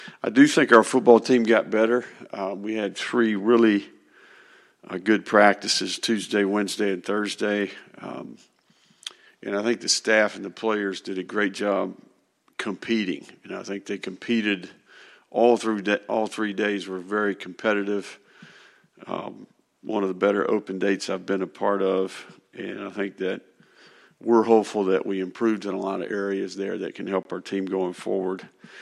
The Gators had intense practices during the bye week in preparation for Saturday’s home game against UCF, Florida football coach Billy Napier said during Monday’s news conference.